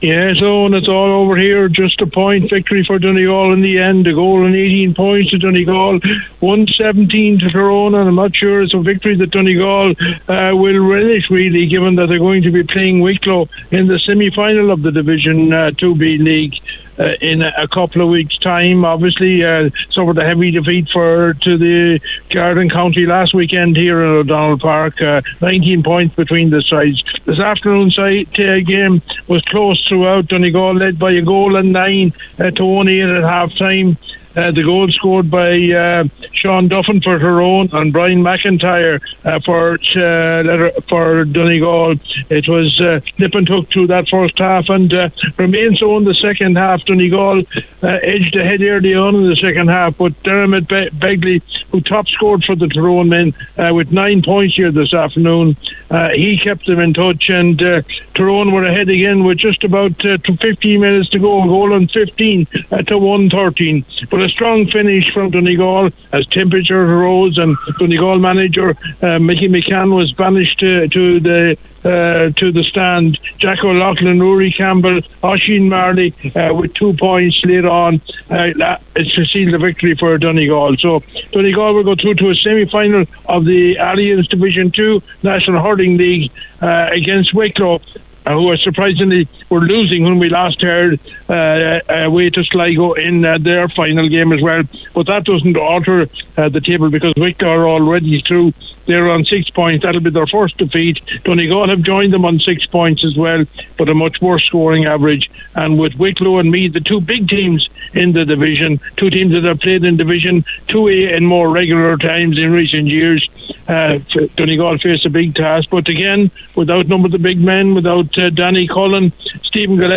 the full time report